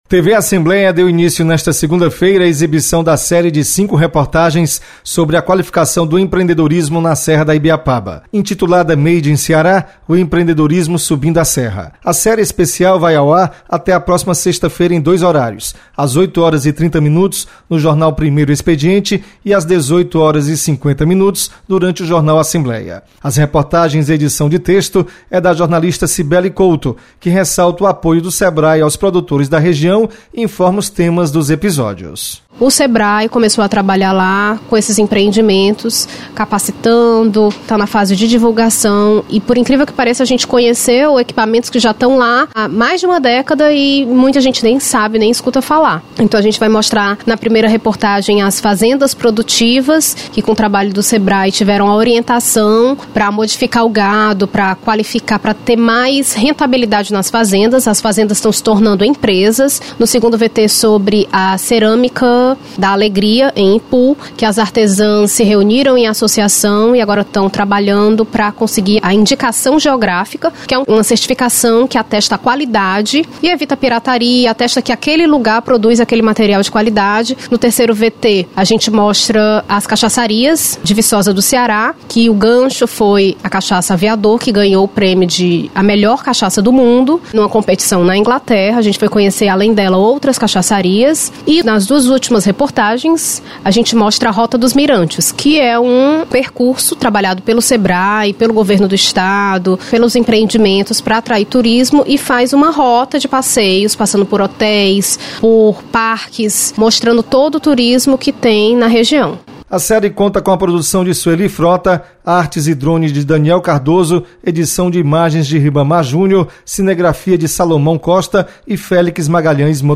Reportagens